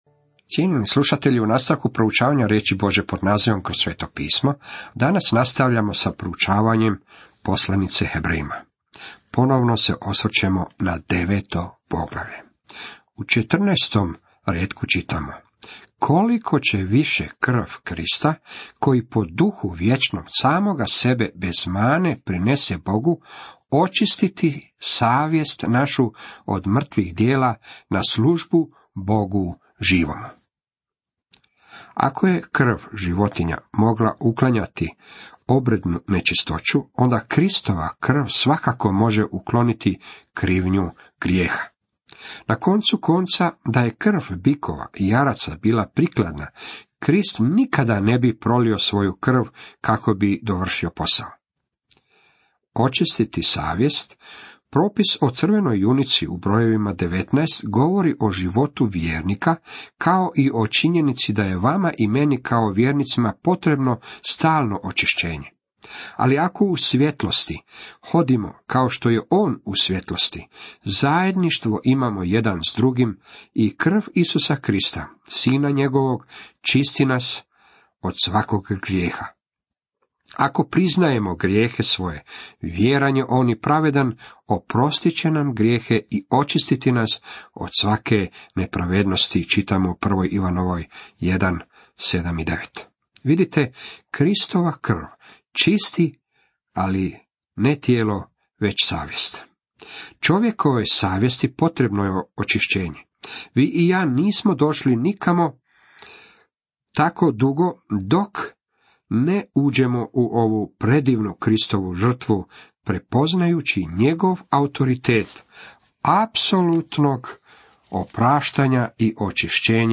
Radio program "Kroz Sveto pismo" je dio svjetske biblijske službe poučavanja pod nazivom "Kroz Sveto pismo".